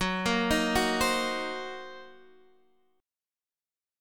Gb6b5 chord